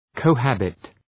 {,kəʋ’hæbıt}